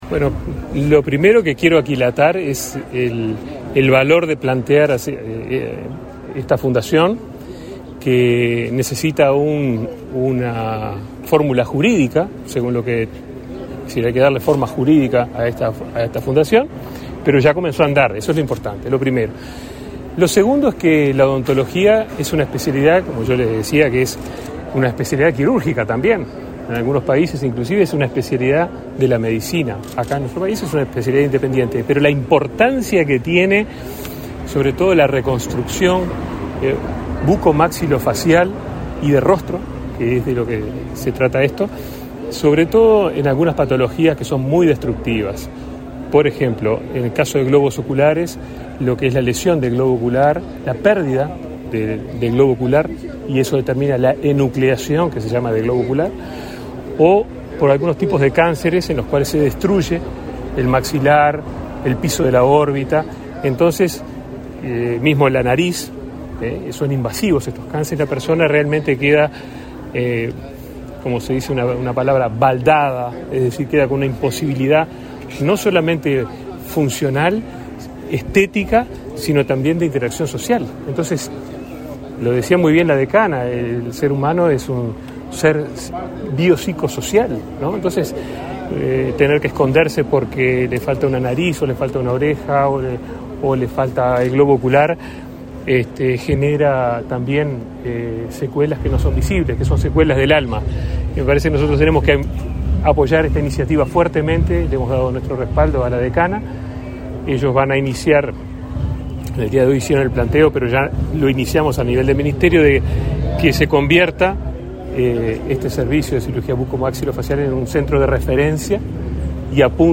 Declaraciones a la prensa del ministro de Salud Pública, Daniel Salinas
Tras el evento, el ministro de Salud Pública, Daniel Salinas, realizó declaraciones a la prensa.